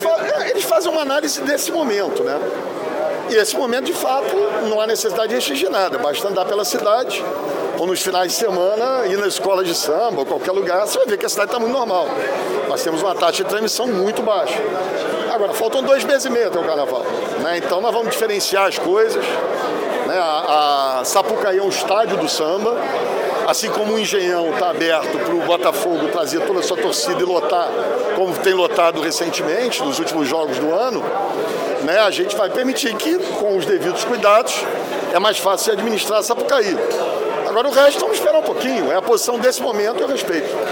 Durante entrevista, nesta quarta-feira, o prefeito Eduardo Paes falou de diversos assuntos. Sobre a privatização do Santos Dumont, Paes disse que vai contestar o modelo de privatização.